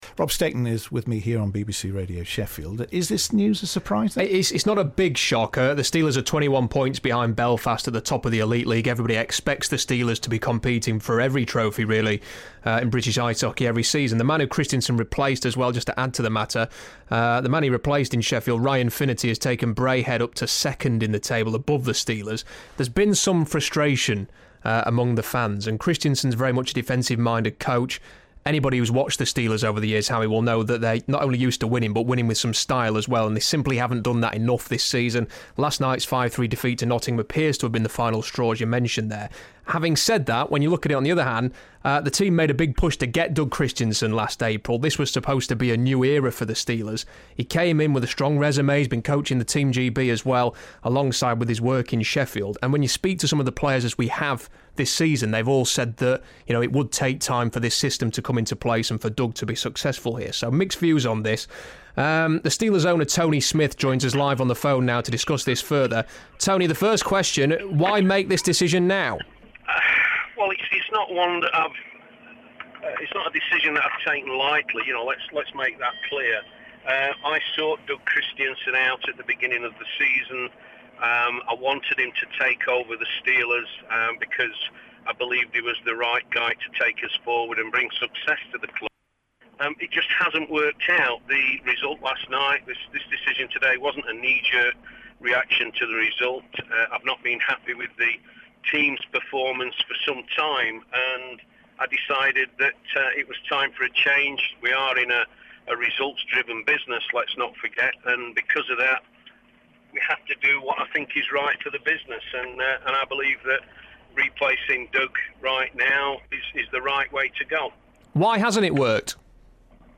interview on BBC Radio Sheffield